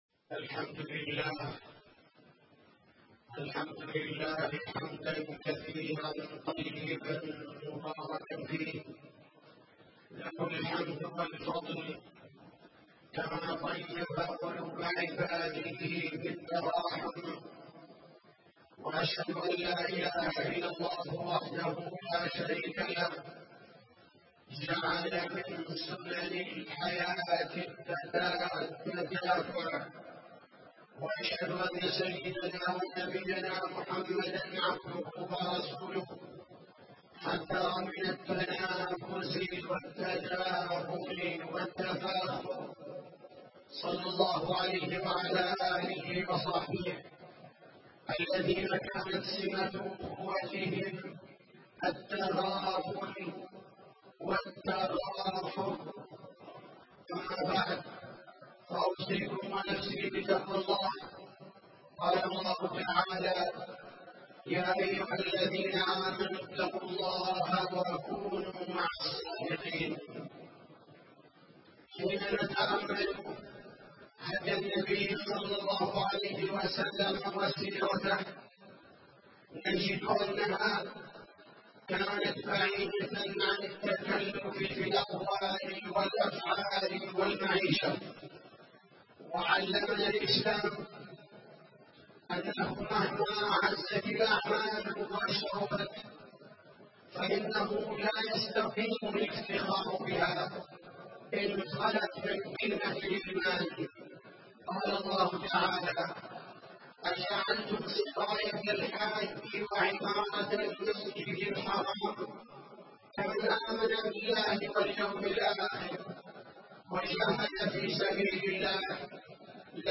تاريخ النشر ٩ ذو القعدة ١٤٣٧ هـ المكان: المسجد النبوي الشيخ: فضيلة الشيخ عبدالباري الثبيتي فضيلة الشيخ عبدالباري الثبيتي من صور التفاخر المذموم